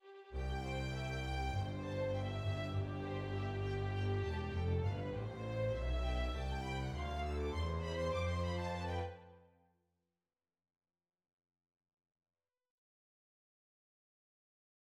리하르트 바그너의 오페라 ''뉘른베르크의 명가수''의 서주에서는 오페라의 세 가지 주제가 동시에 결합된다.